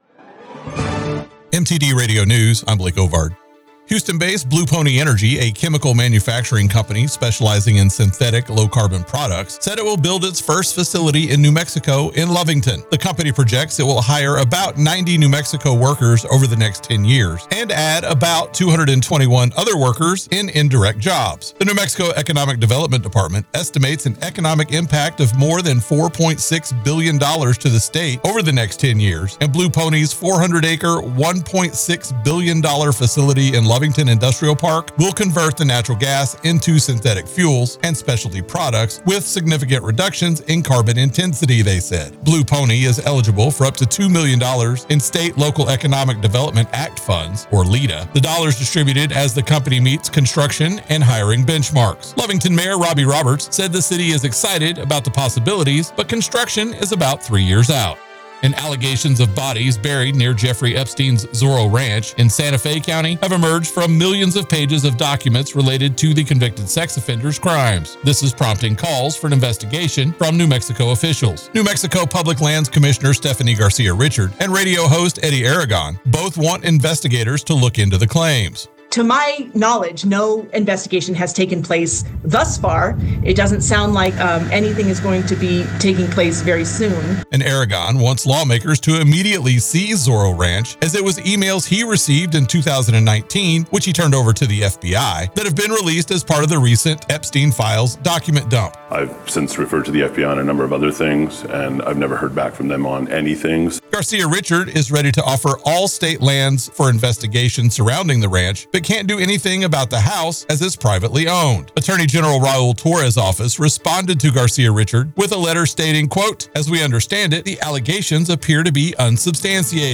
W105 News – New Mexico and West Texas